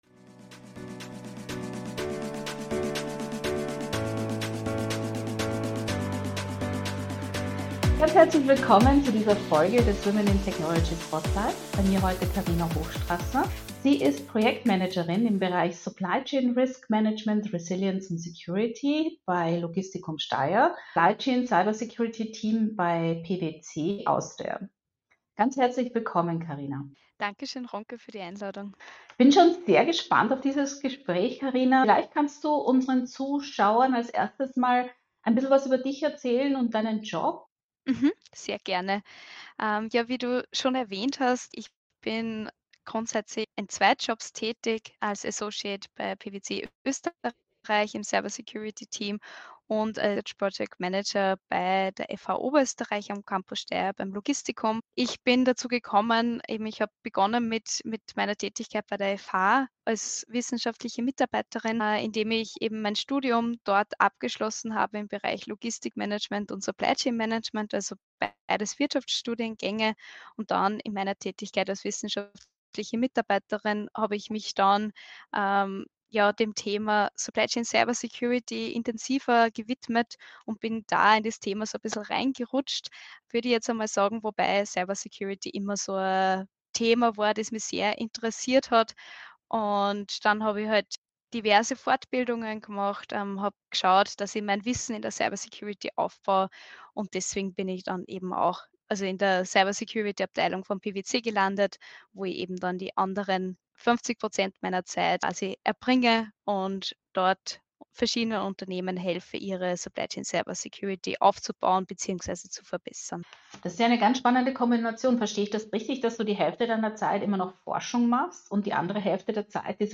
Expert Talk